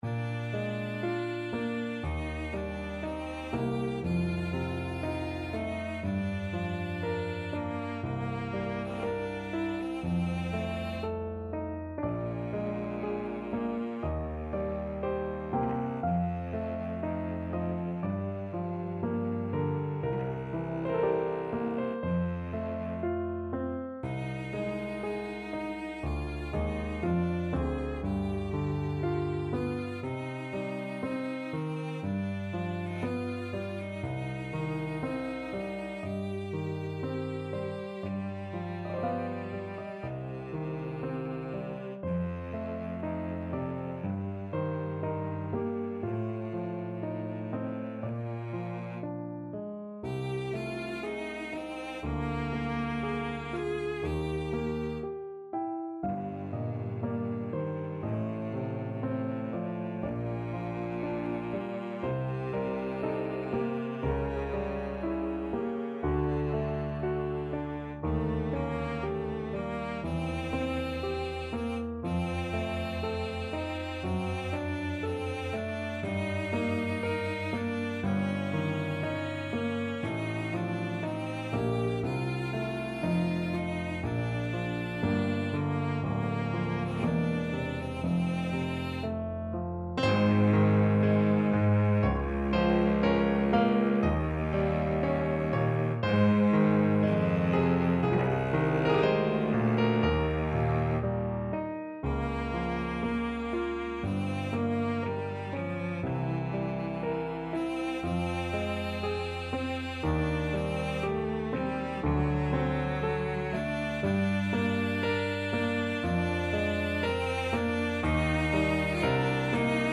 Free Sheet music for Cello
Cello
Bb major (Sounding Pitch) (View more Bb major Music for Cello )
3/2 (View more 3/2 Music)
~ = 60 Largo
Classical (View more Classical Cello Music)